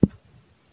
beeb kick 11
Tags: 808 drum cat kick kicks hip-hop